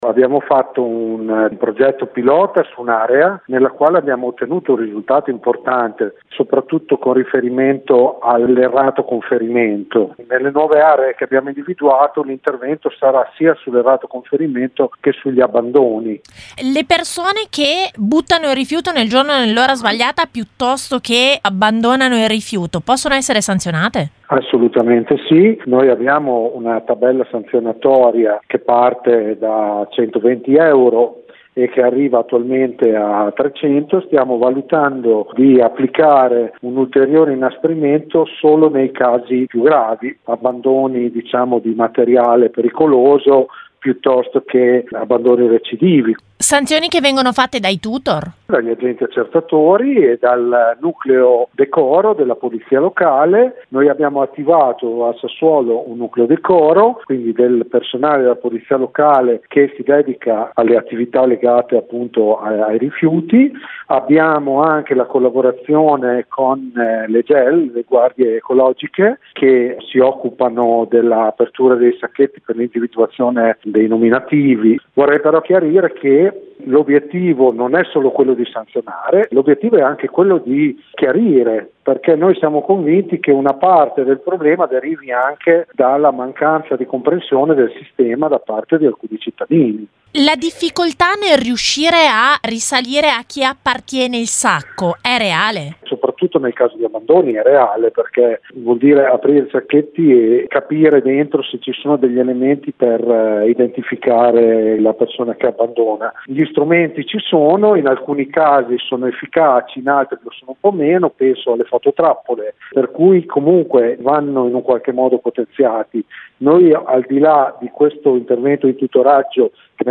L’impiego di tutor funziona, secondo l’assessore all’ambiente Andrea Baccarani, qui intervistato